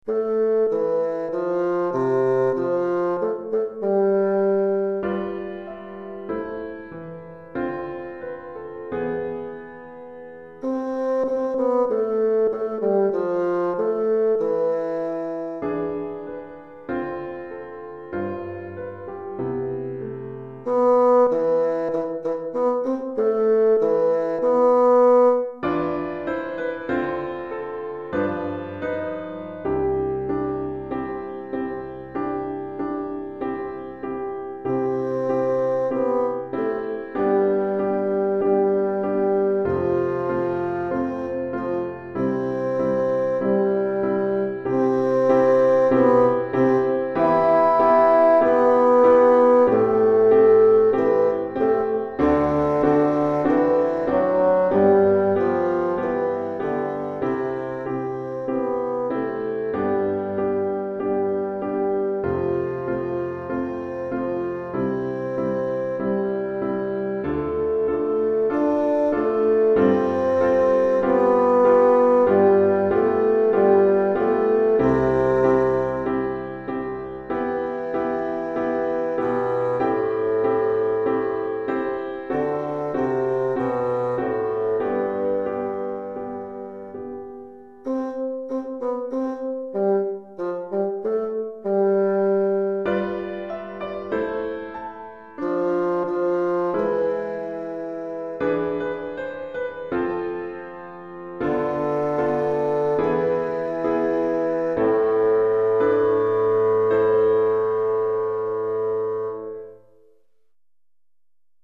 Pour basson et piano DEGRE CYCLE 1